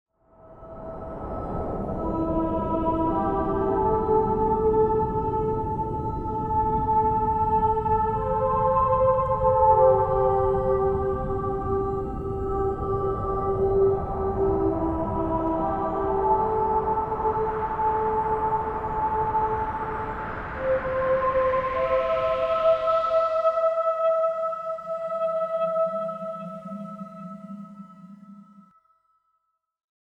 E minor